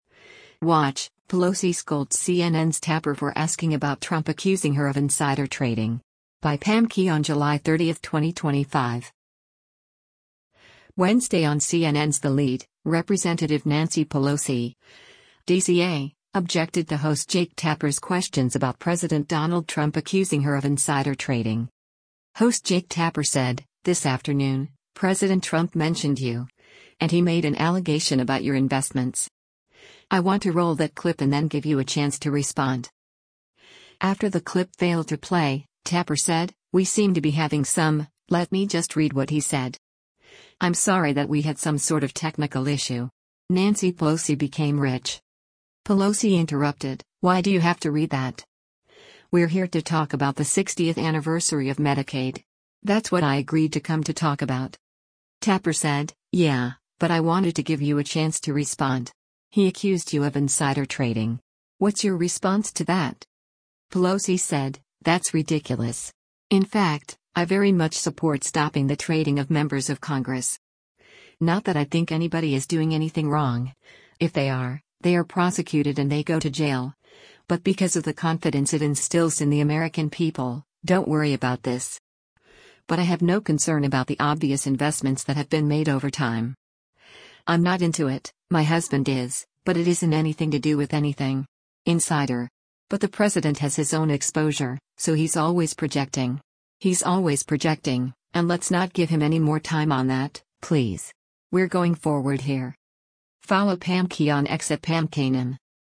Wednesday on CNN’s “The Lead,” Rep. Nancy Pelosi (D-CA) objected to host Jake Tapper’s questions about President Donald Trump accusing her of insider trading.